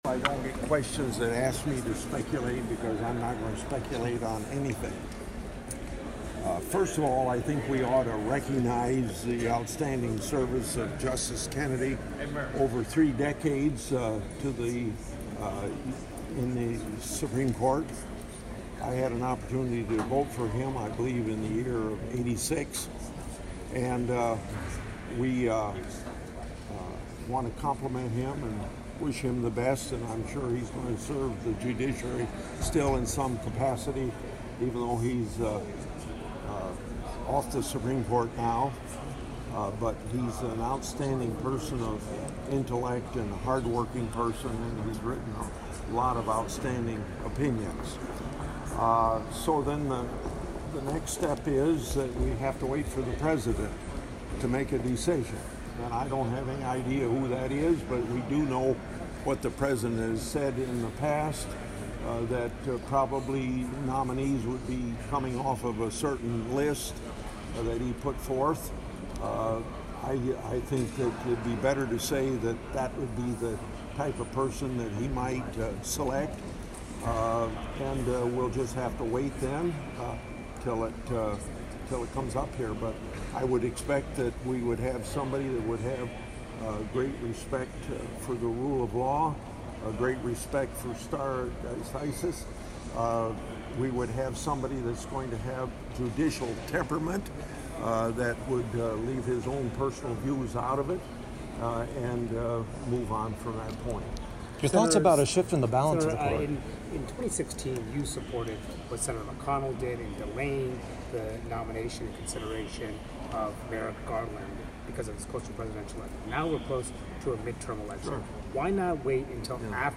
Grassley's Speaks to Media Following Justice Kennedy Retirement